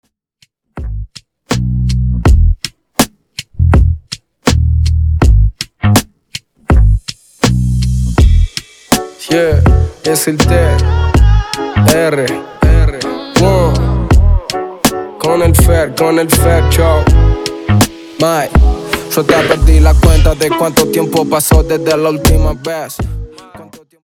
Extended Dirty Intro